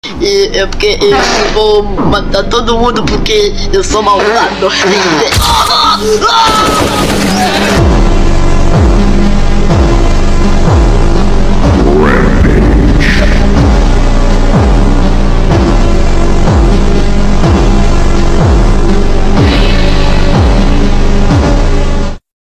Ultra - Slowed